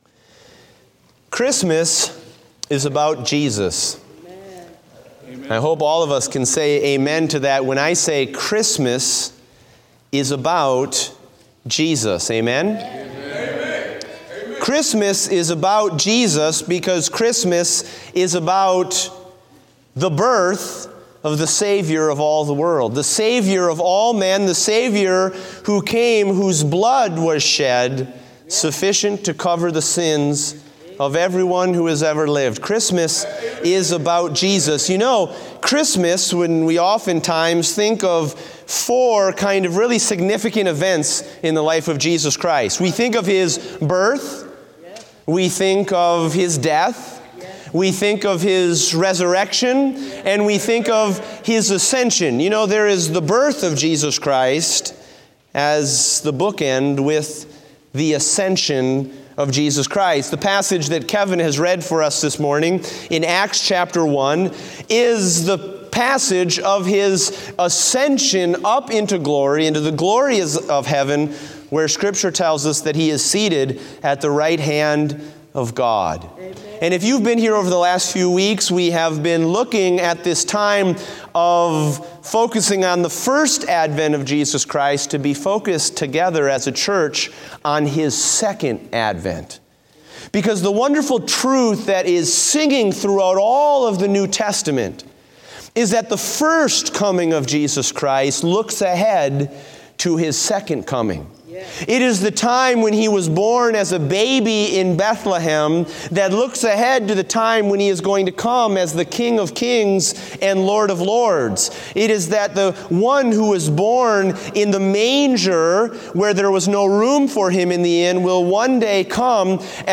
Date: December 25, 2016 (Morning Service)